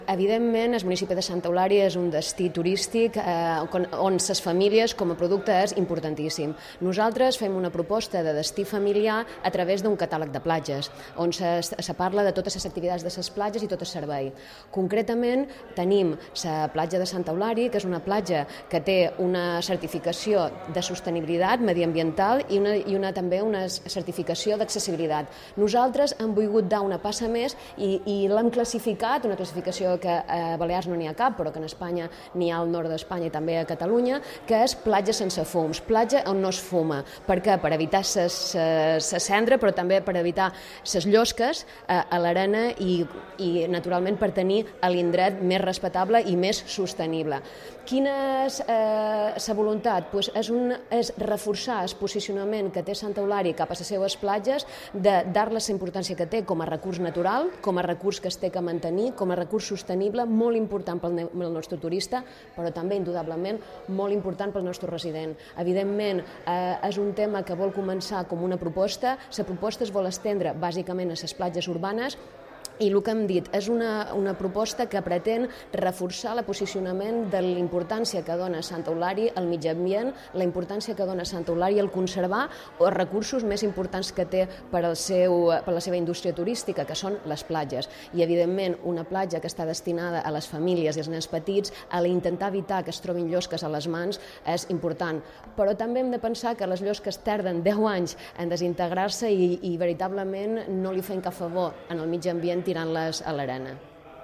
DECLARACIONES EDIFICIO SANT CARLES